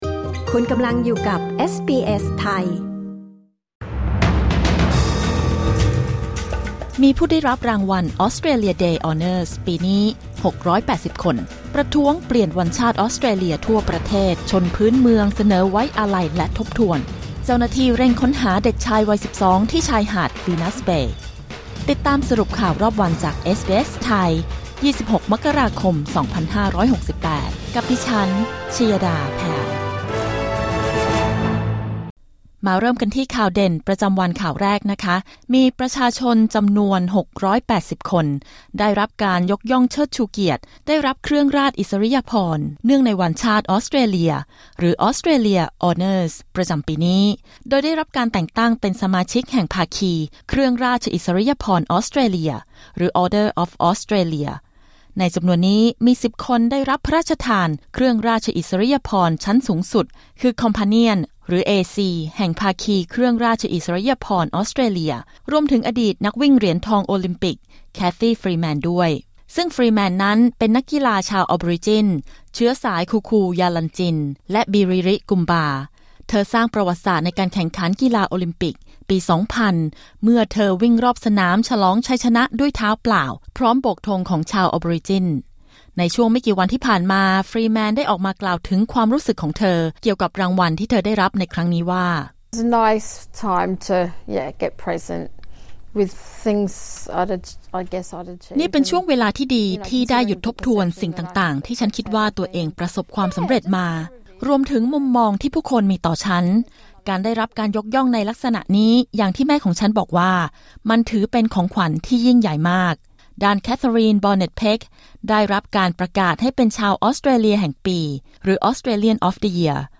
สรุปข่าวรอบวัน 26 มกราคม 2569